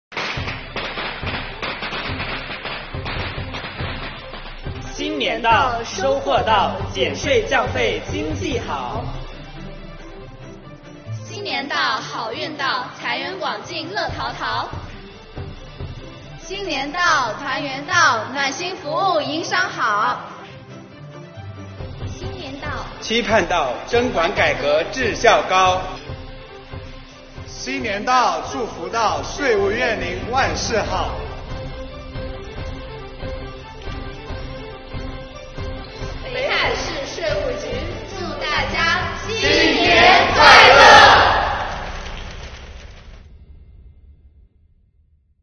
值此新春佳节之际广西各地税务干部用短视频向您送来最诚挚的新春祝福。